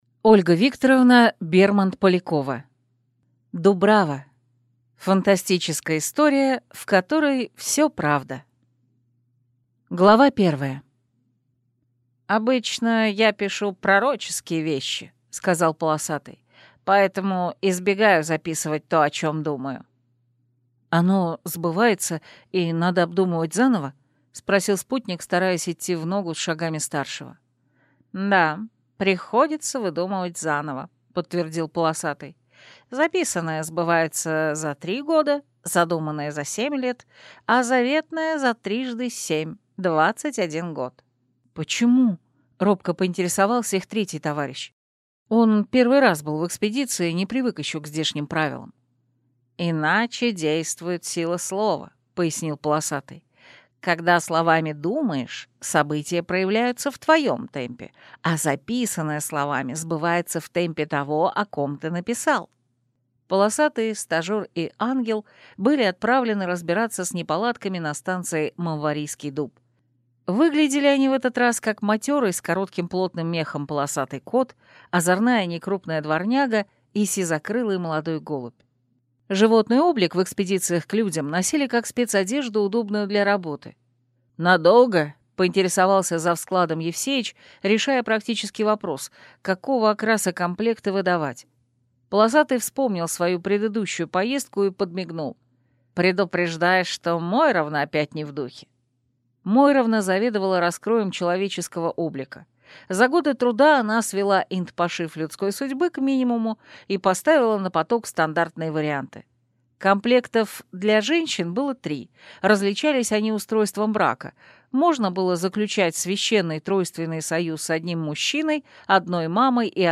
Аудиокнига Дубрава. Фантастическая история, в которой всё – правда | Библиотека аудиокниг